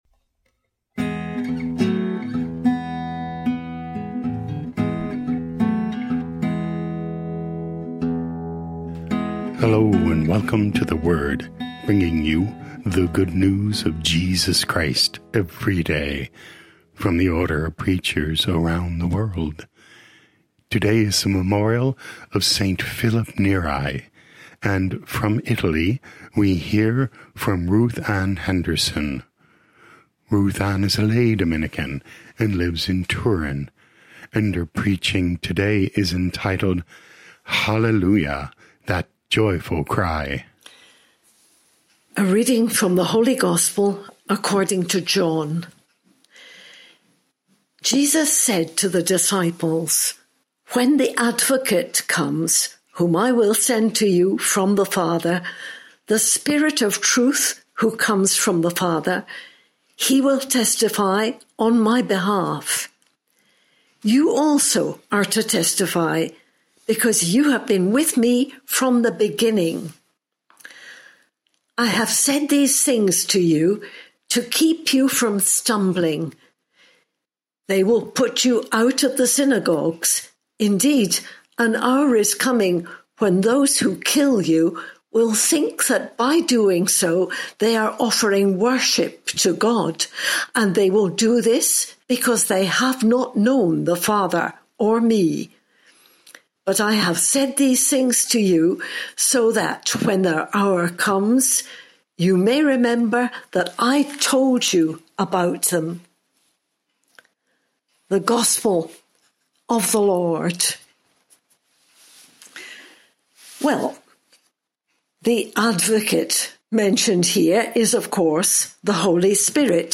Preaching